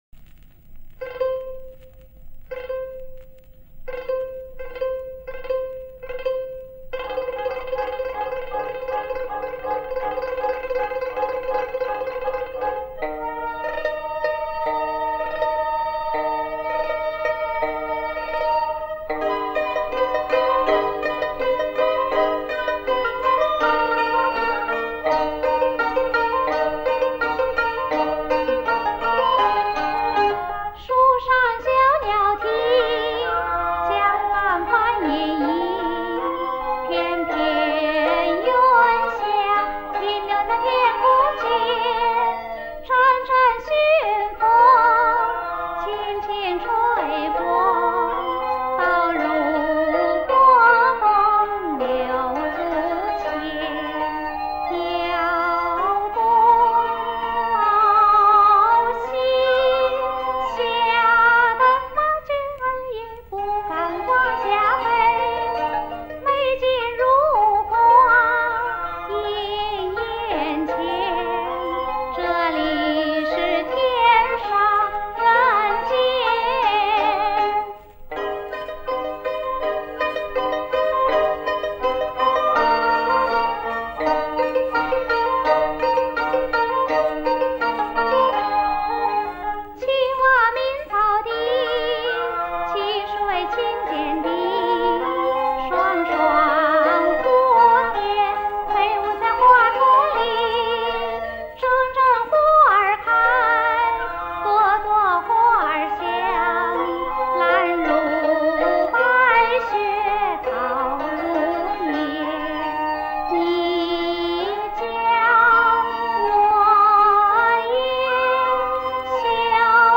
如常，质方面，应考虑到歌曲都是几十年前的录音，调整一下